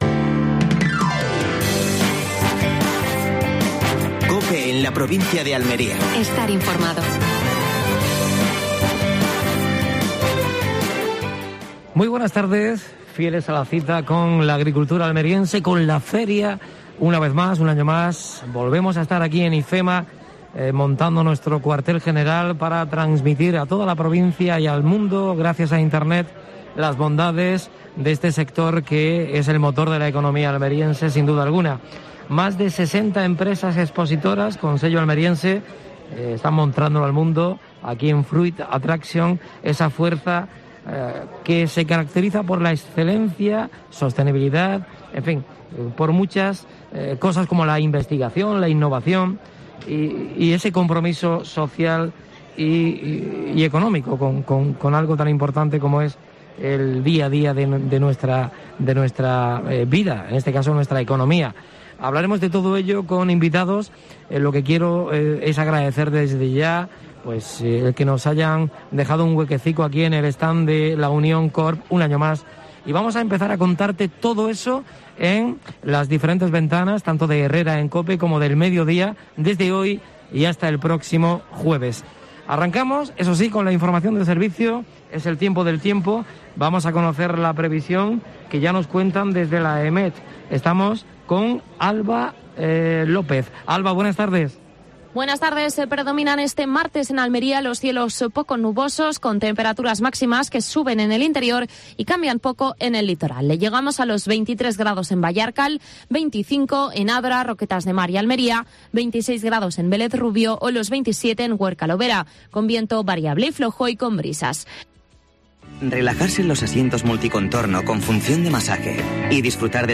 Primer programa especial de Fruit Attraction desde el estand de La Unión Corp.